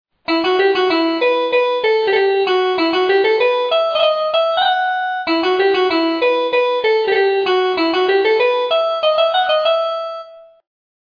The first two bars, further repeated in the theme, contain all notes characteristic of a minor key and mode.